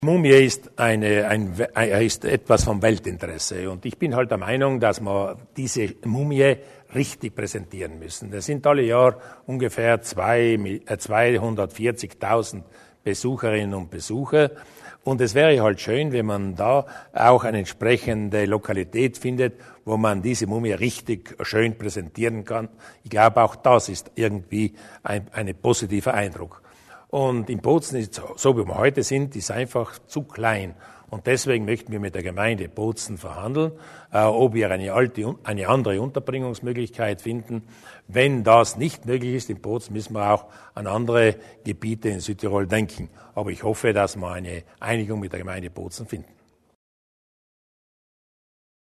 Landeshauptmann Durnwalder über die zukünftige Unterbringung des Ötzi